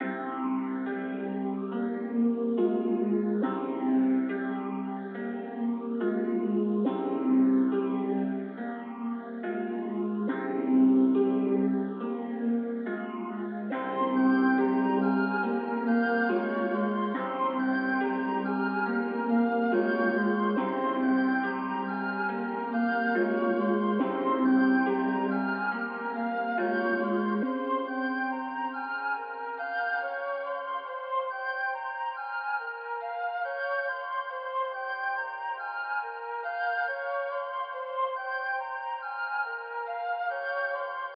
808 mafia 140bpm.wav